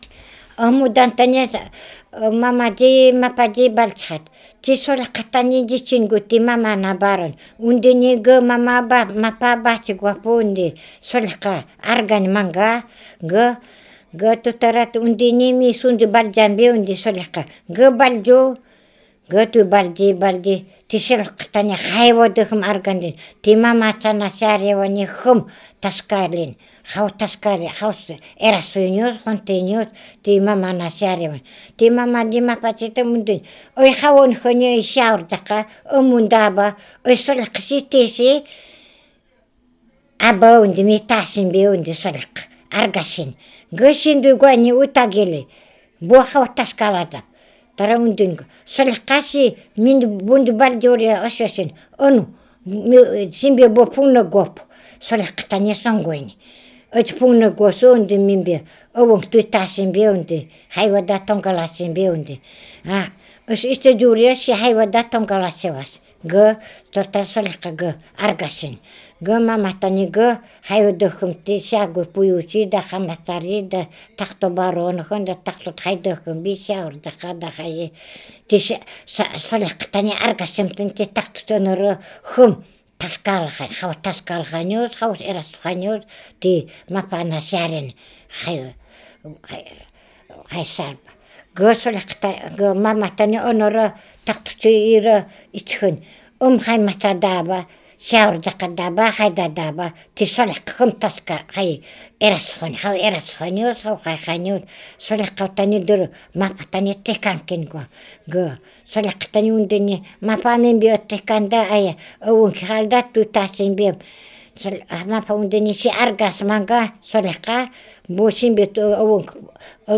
2) Аудиозаписи речи .
болонский говор